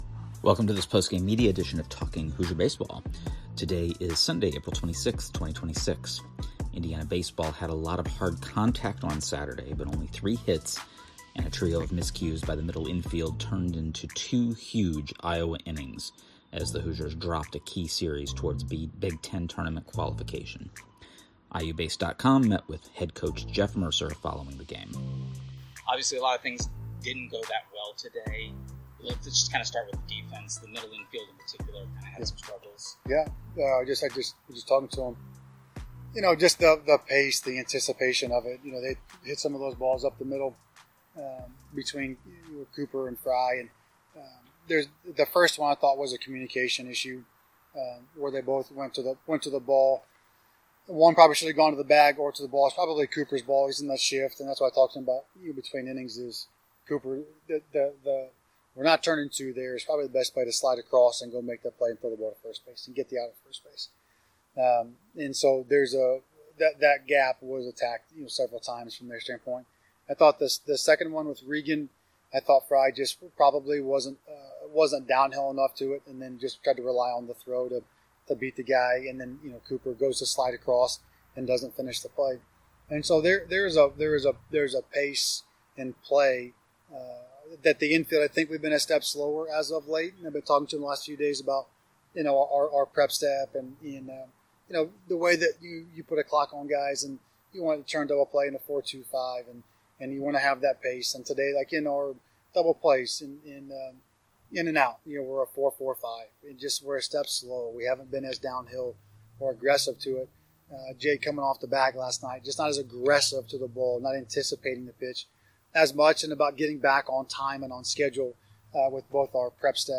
Postgame Media – Iowa Saturday